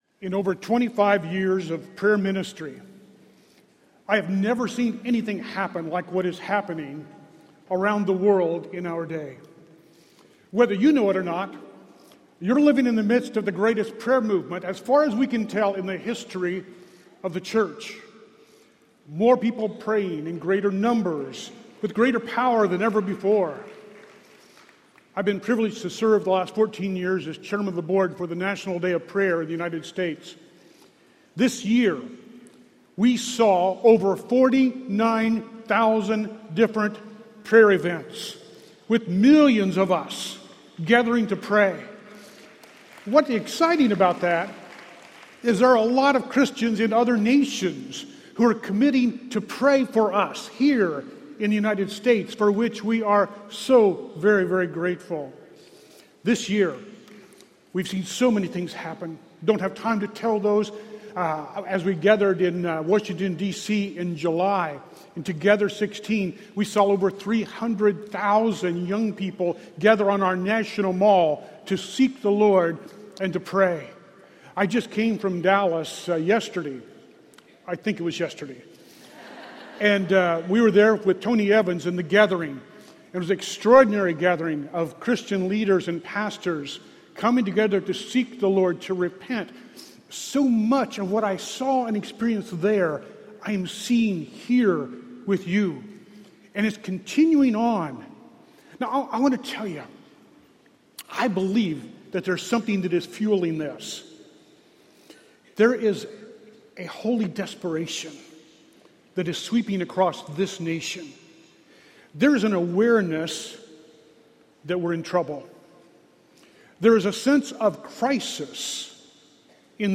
Praying for the Nation | True Woman '16 | Events | Revive Our Hearts